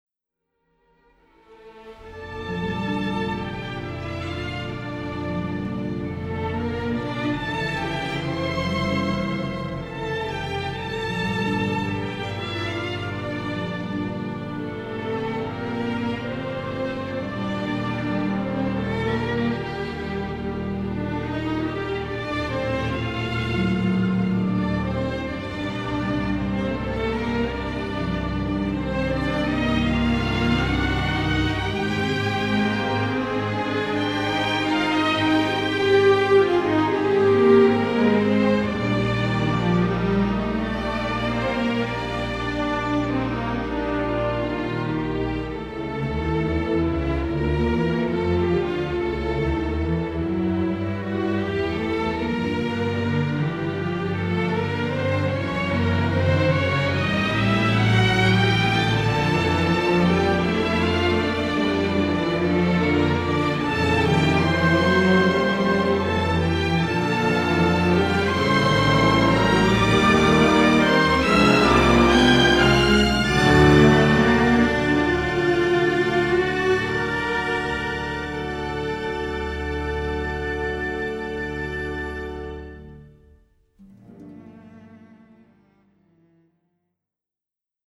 unabashedly romantic
suspenseful and brooding, pastoral and uplifting